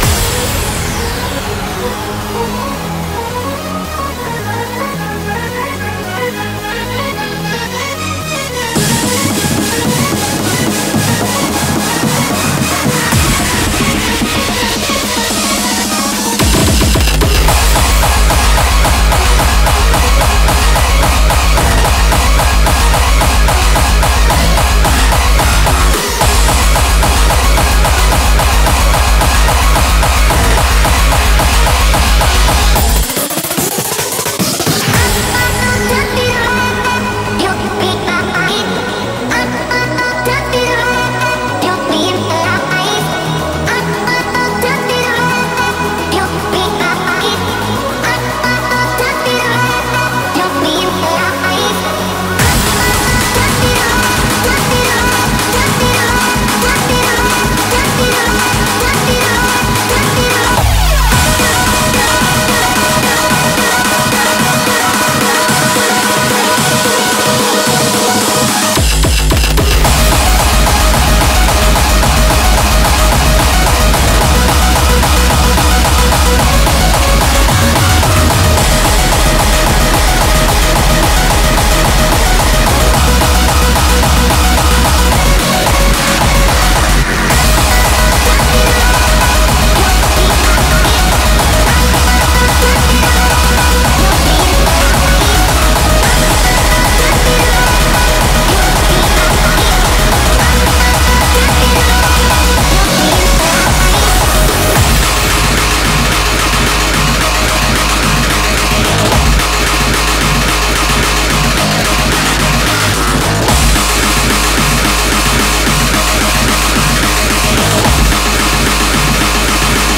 BPM220
Audio QualityPerfect (High Quality)
Comments[GABBER SPEED]